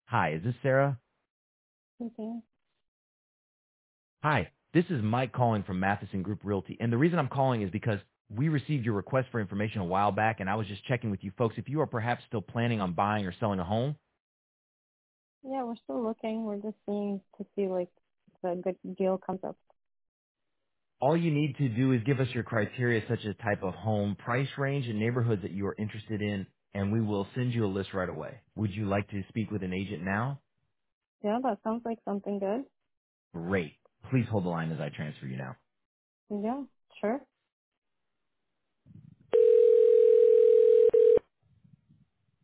Experience Ultra-Realistic AI Voice Agent